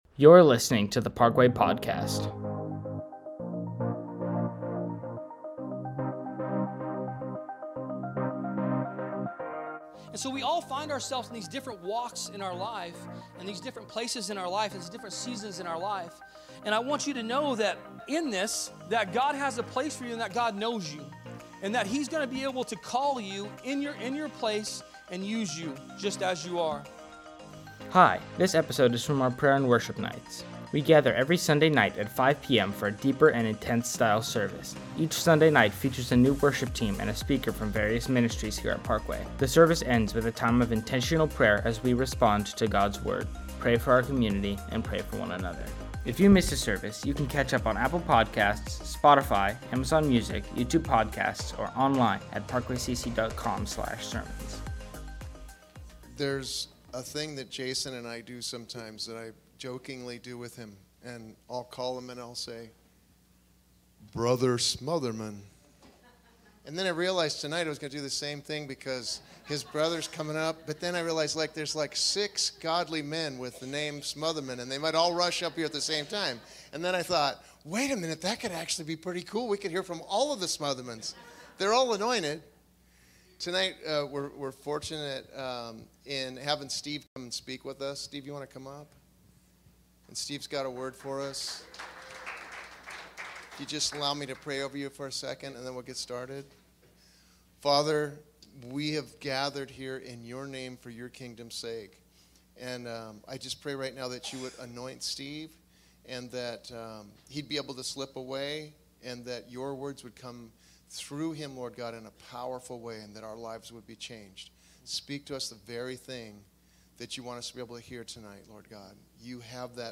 A message from the series "Prayer & Worship Nights."